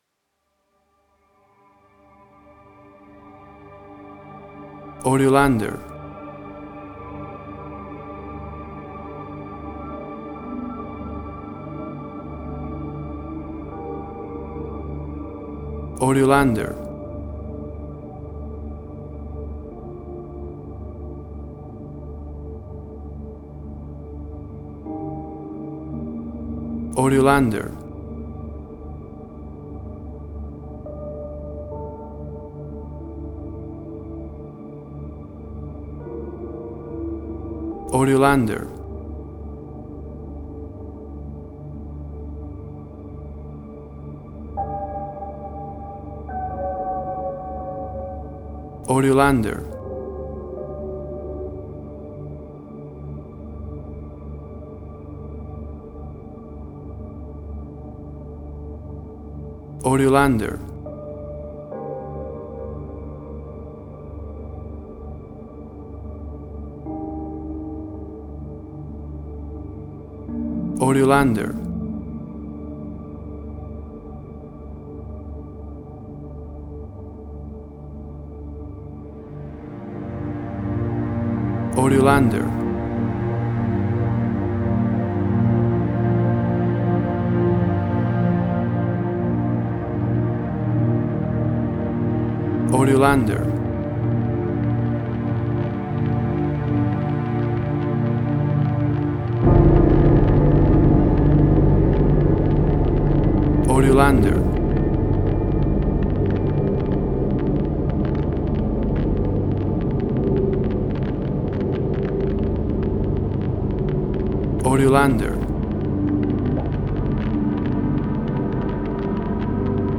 Post-Electronic.